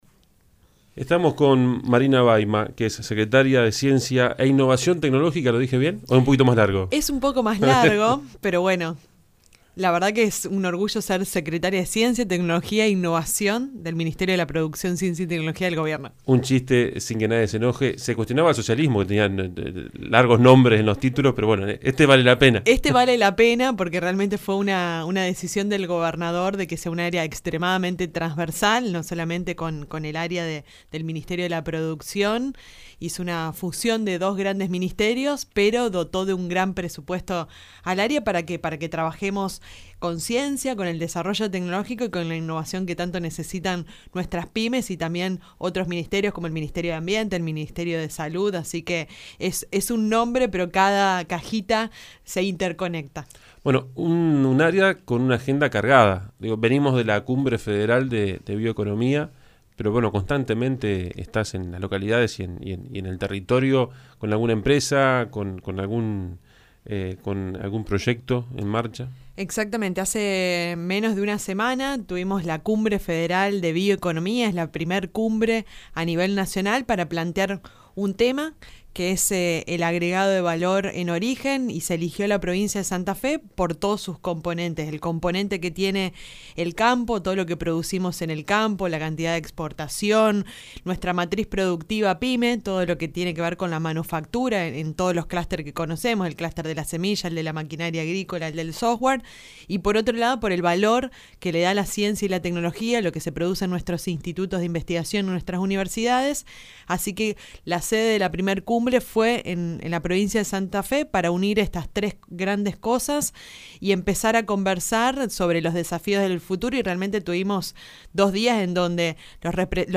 En 341Radio, Baima se refirió al famoso corredor Rosario-Rafaela/Sunchales-Santa Fe y lo definió cómo el triángulo de las ciencias de la vida y la biotecnología.